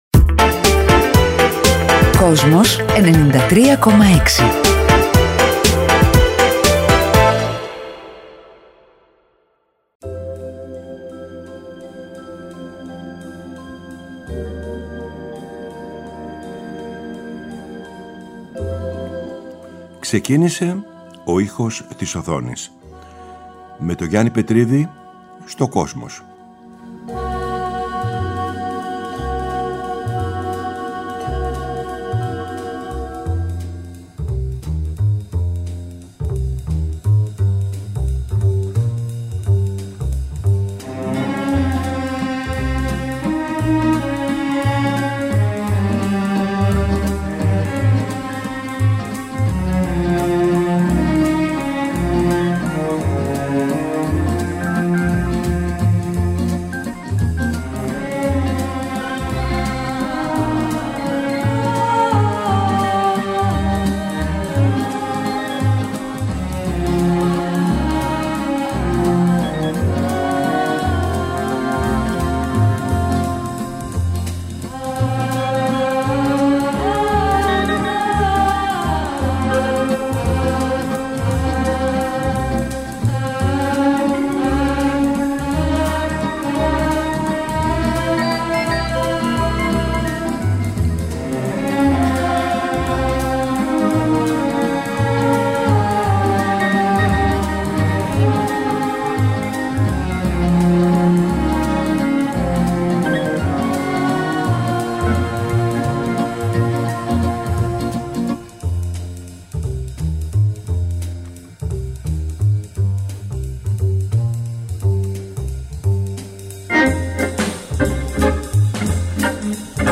Κάθε Κυριακή 18:00-19:00 ο Γιάννης Πετρίδης παρουσιάζει μία σειρά αφιερωματικών εκπομπών για το Kosmos, με τον δικό του μοναδικό τρόπο.